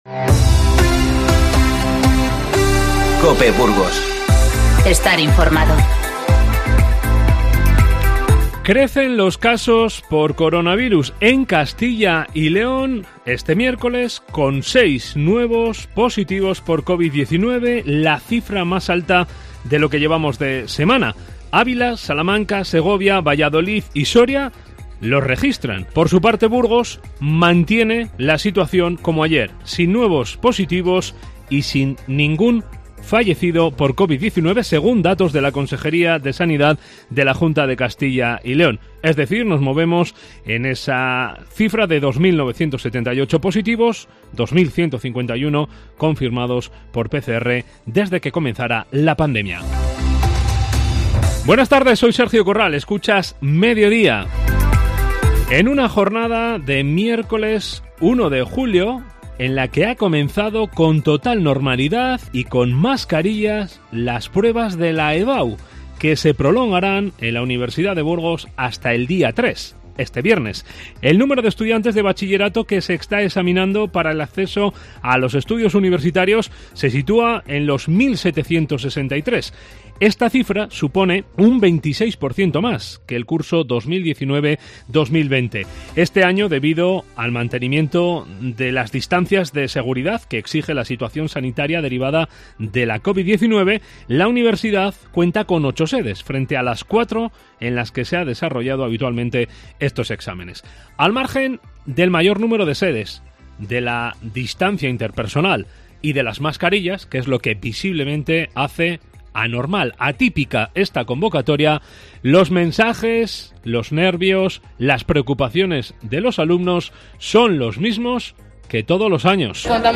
INFORMATIVO MEDIODÍA 1-7-2020